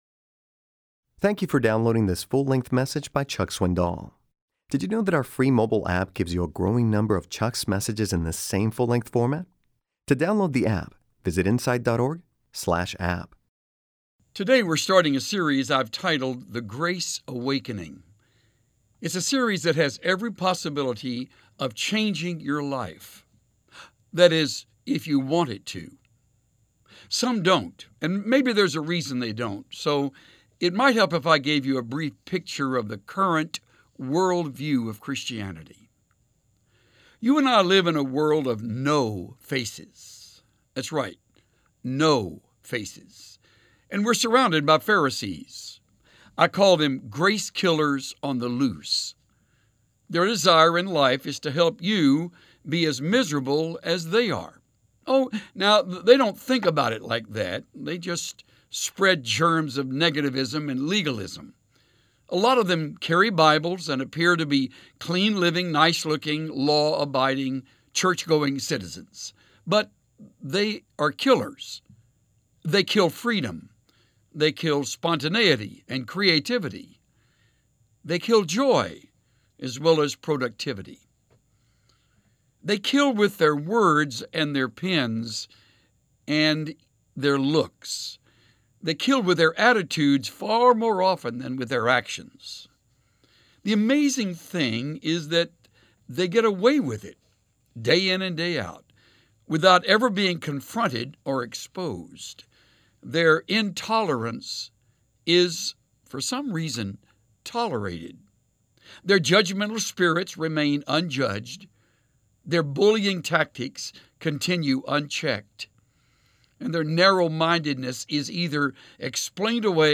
Join Chuck Swindoll as he delivers a first-person account of Jesus’s Passion Week.